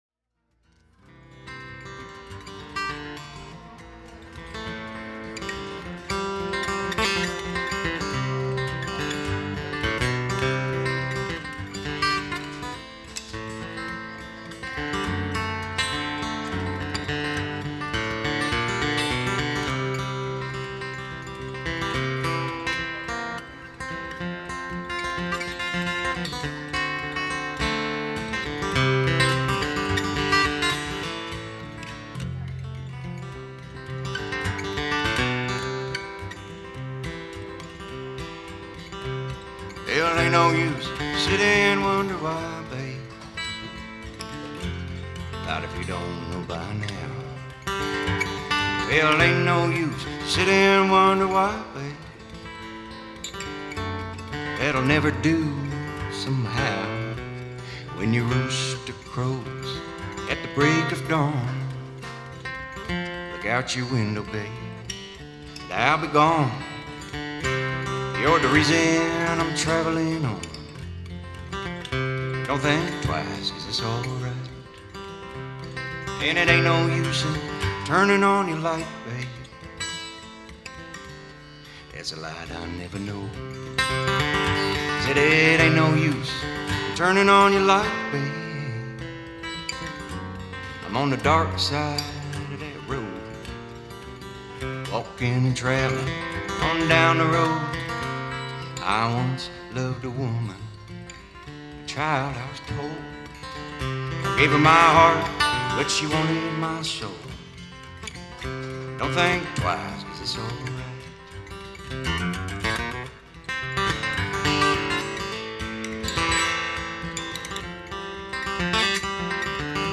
Guitar/Banjo/Vocals
Percussion/Vocals
Harmonica/Vocals
Bass